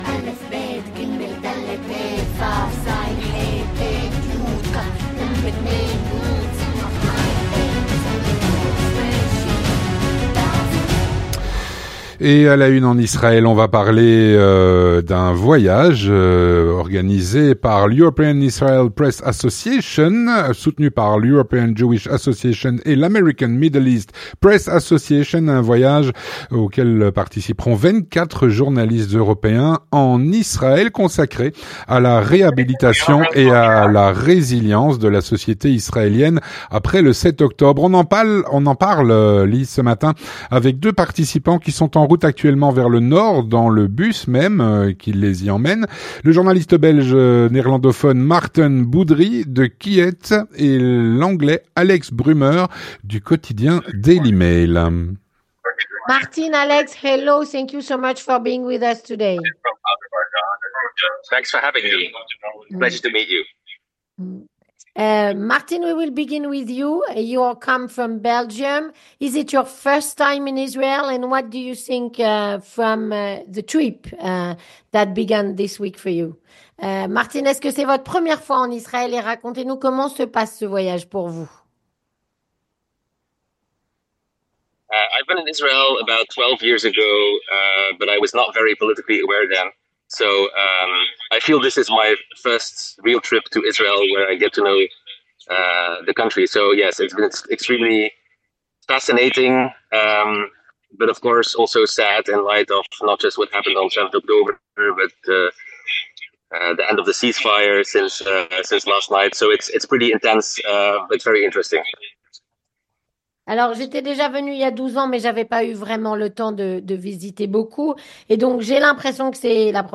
On en parle avec deux participants, qui sont en route actuellement vers le Nord dans le bus